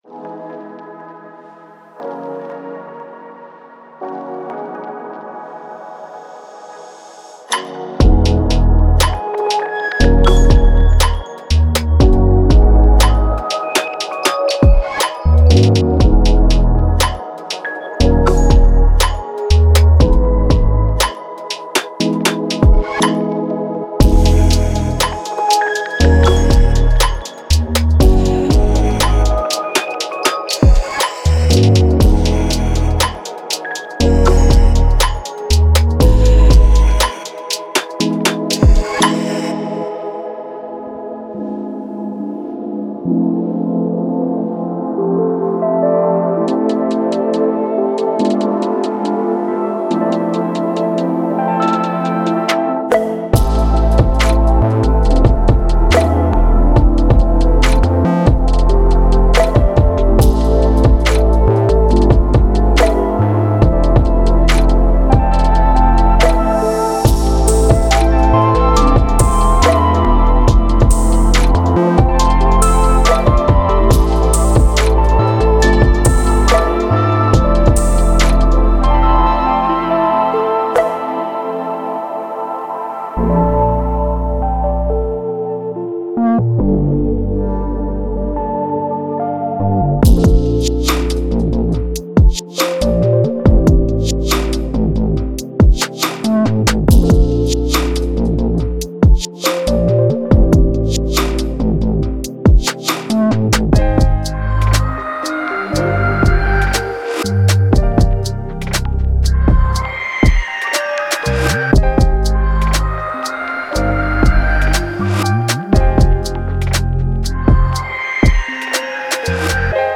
Genre:Future RnB
デモサウンドはコチラ↓
18 Bass Loops
15 Synth Loops